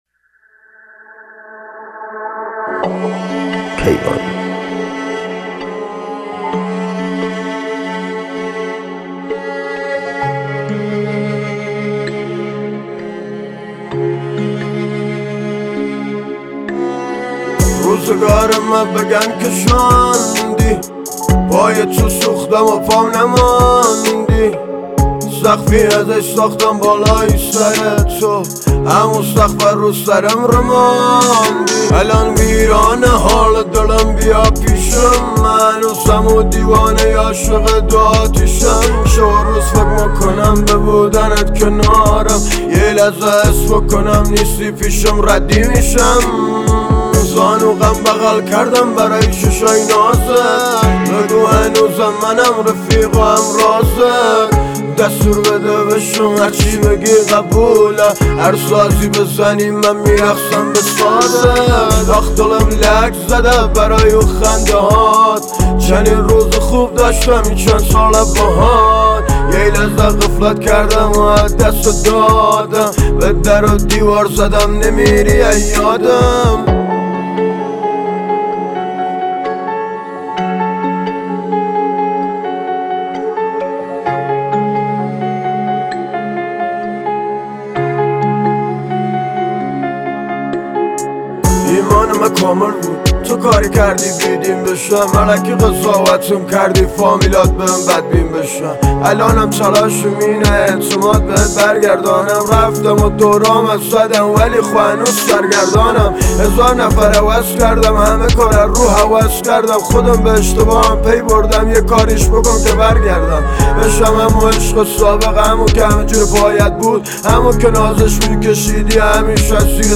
موزیک کردی